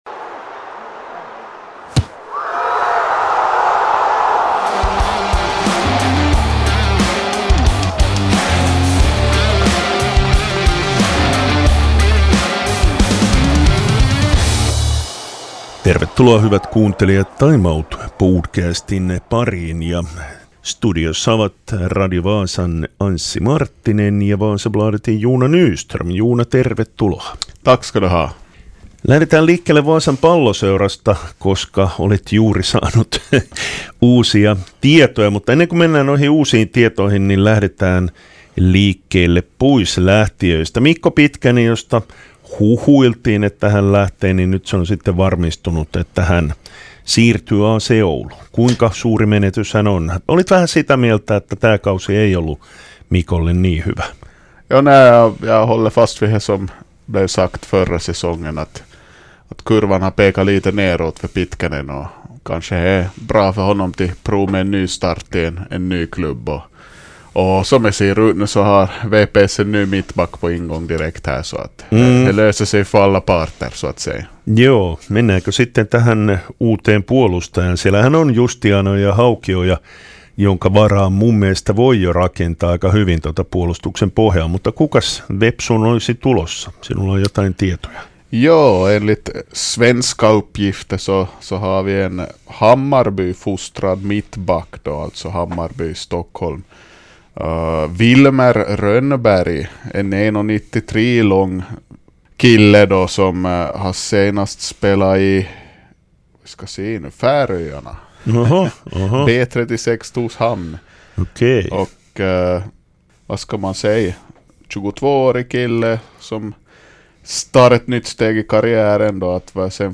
I studion